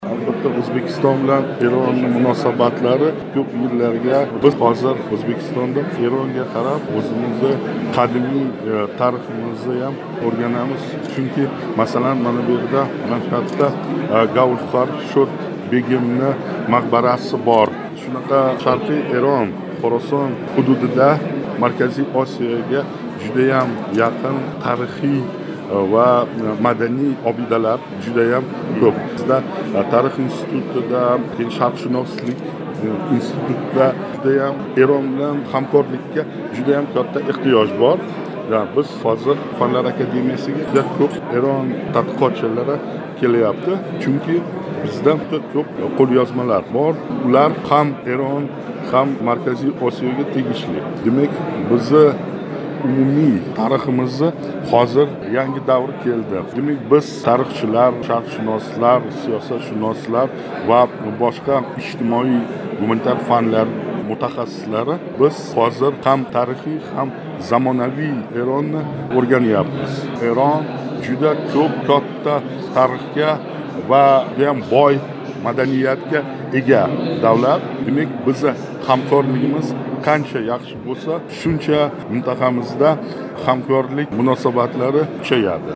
Ушбу суҳбатни эътиборингизга ҳавола этамиз.